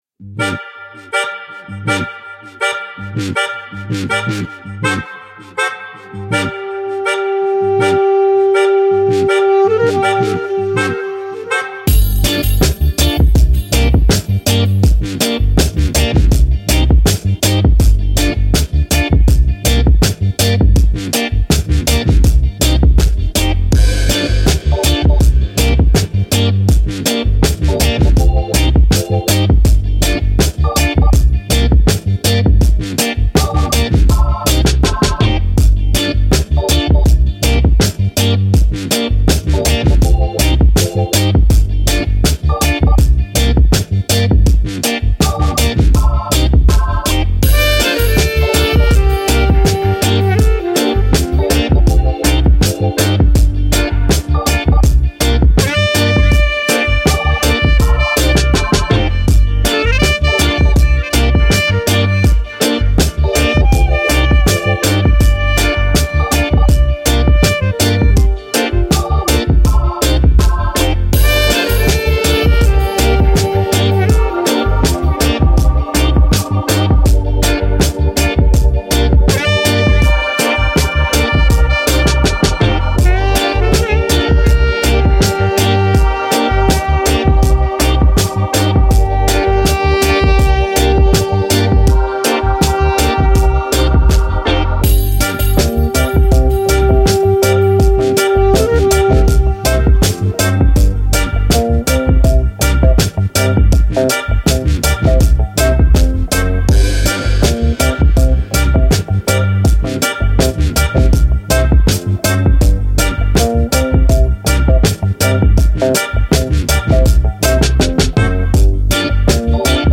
Hip Hop, Dub, Action, Upbeat, Confident